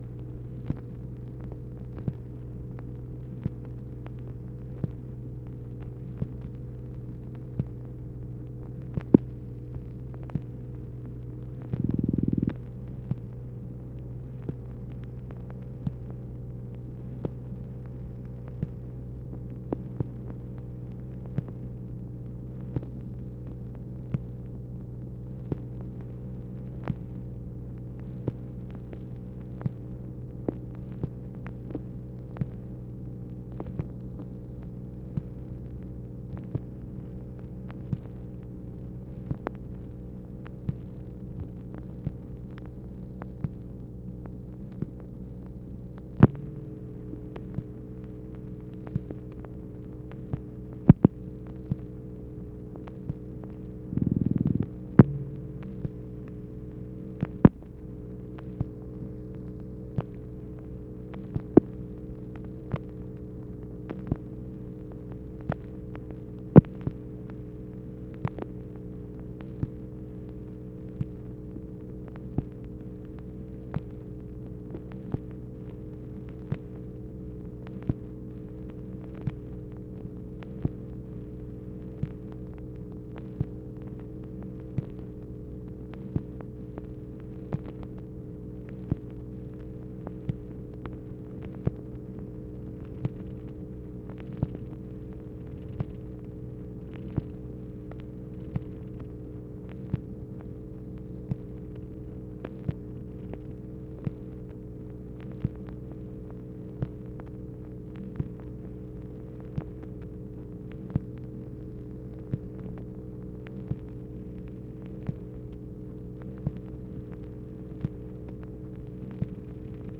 MACHINE NOISE, January 25, 1964
Secret White House Tapes | Lyndon B. Johnson Presidency